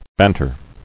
[ban·ter]